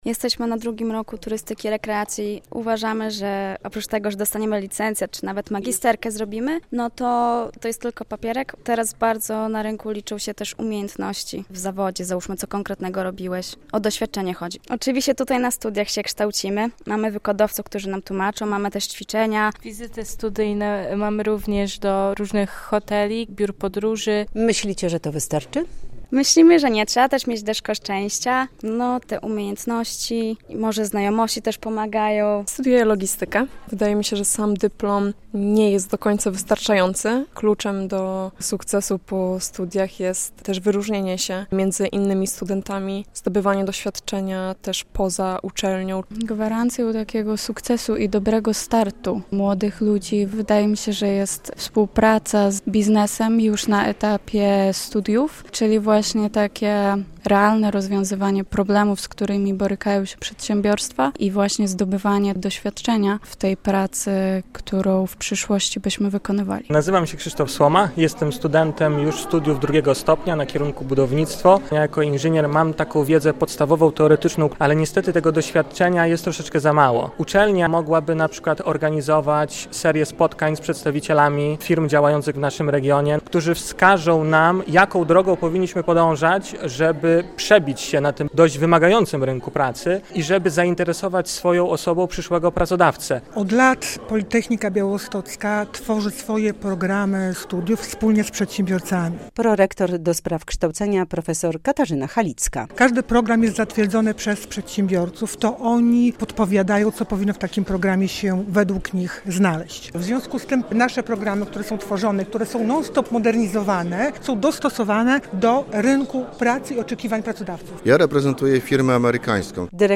Dyplom dziś już nie wystarcza - debata na politechnice
W debacie o kompetencjach przyszłości na Politechnice Białostockiej uczestniczyli przedstawicieli środowiska akademickiego, przedsiębiorcy i studenci.